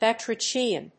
音節ba・tra・chi・an 発音記号・読み方
/bətréɪkiən(米国英語)/
batrachian.mp3